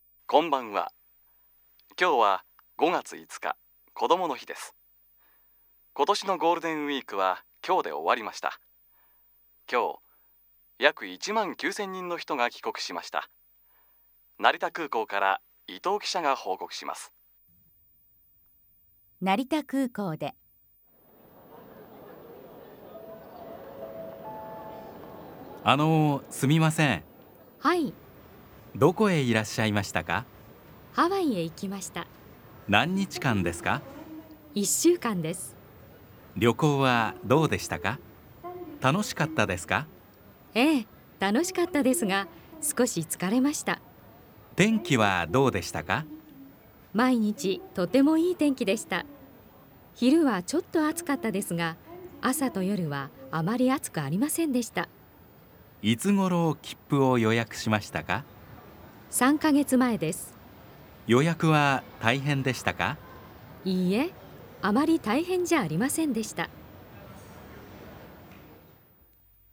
Audio_2_Interview.m4a